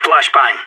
CSGO Flashbang Sound Effect Free Download
CSGO Flashbang